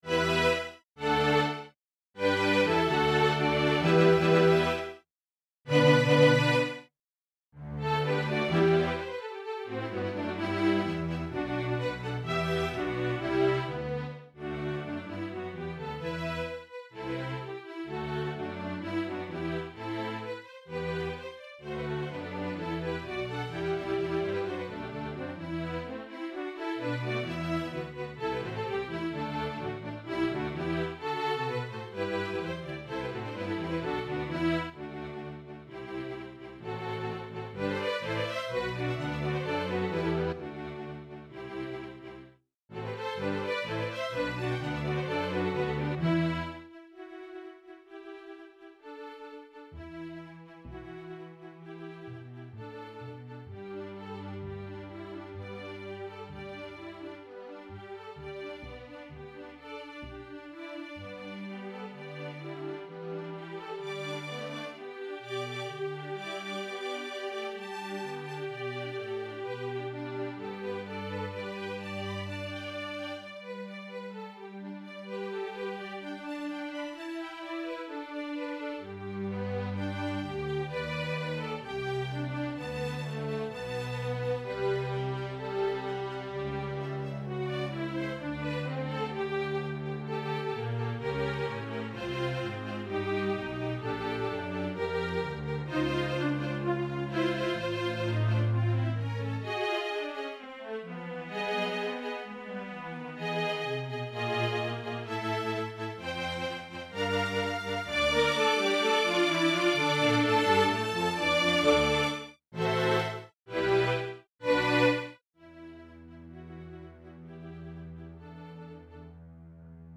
Genre: String Orchestra
Violin I
Violin II
Viola
Cello
Contrabass